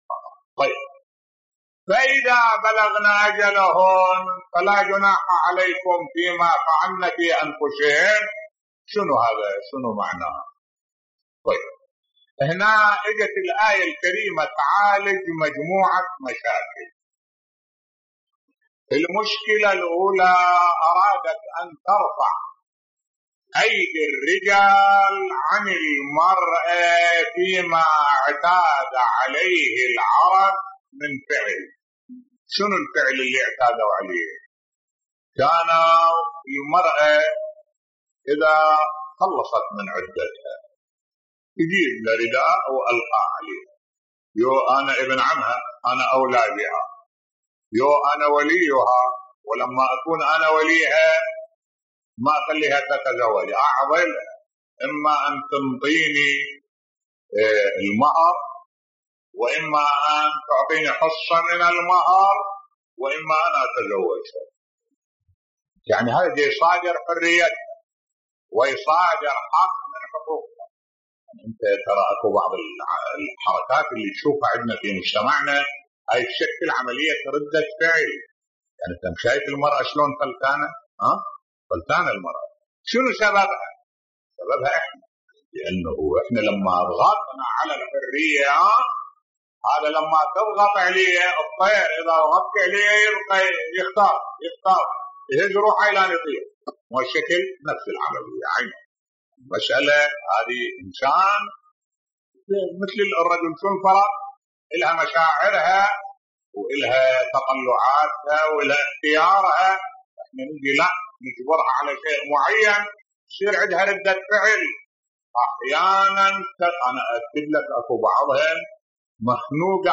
ملف صوتی انفلات المرأة ردة فعل على تقييد حريتها بصوت الشيخ الدكتور أحمد الوائلي